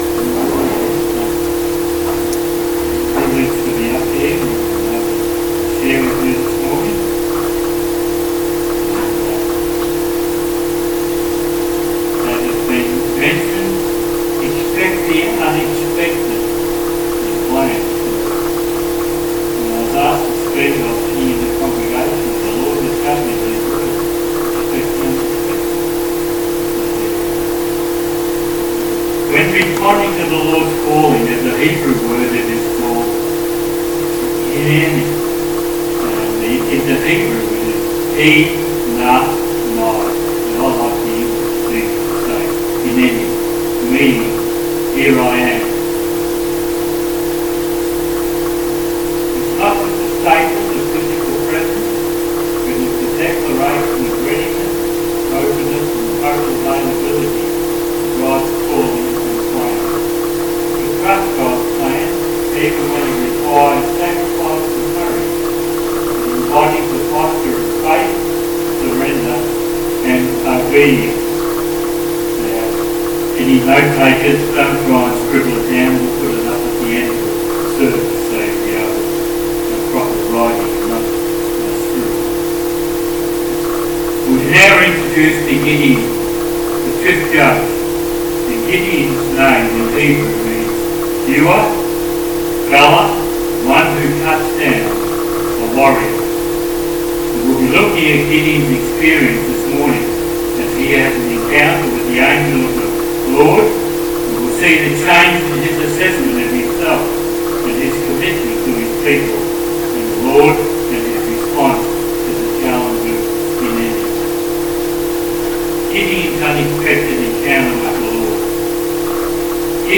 10am Service Sermon